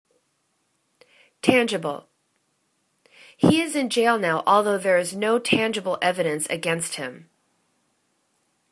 tan.gi.ble      /'tandgibəl/    adj